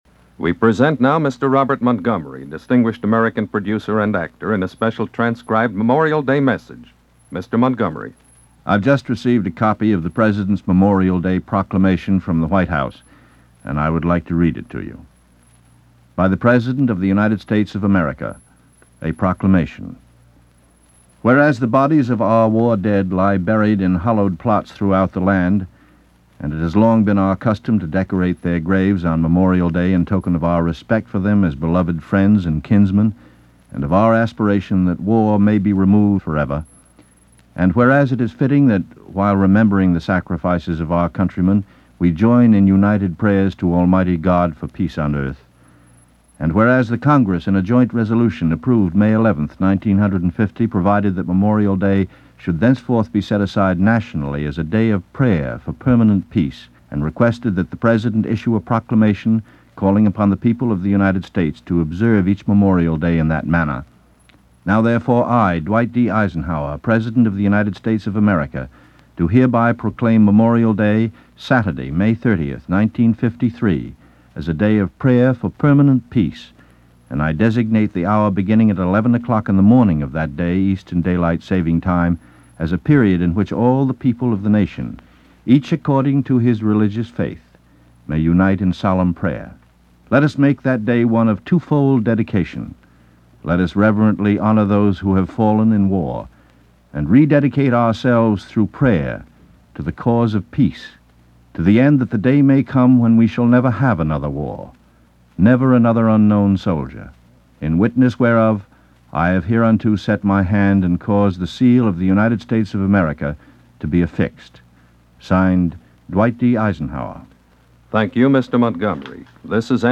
Here is President Eisenhower‘s declaration as read by actor Robert Montgomery for Memorial Day in 1953.